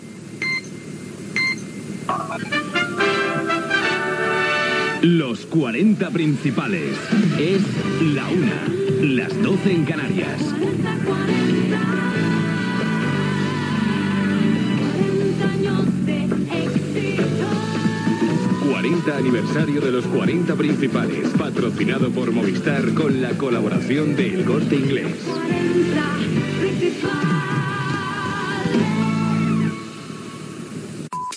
Identificació horària i indicatiu del 40è aniversari de Los 40 Principales
FM